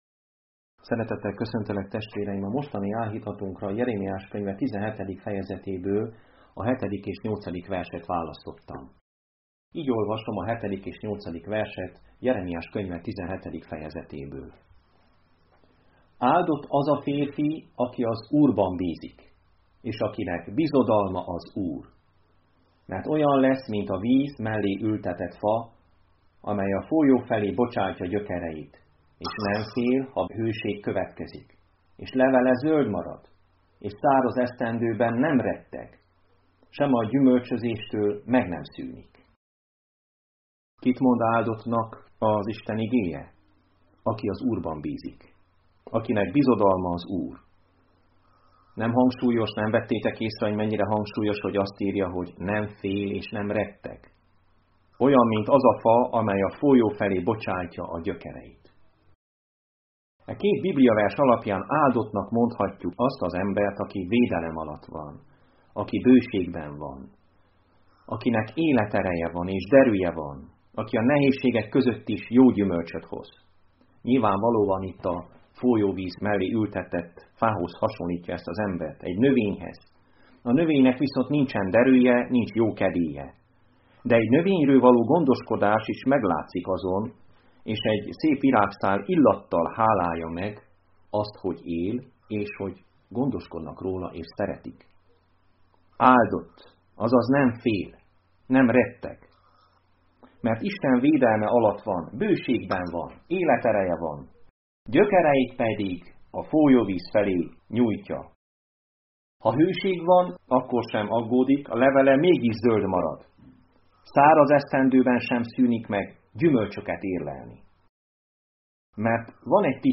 Áldott az ember, aki az Úrban bízik Igehirdetések mp3 Link az igehirdetéshez Hasonló bejegyzések Igehirdetések mp3 Ébredj fel a lelki halálból és felragyog neked...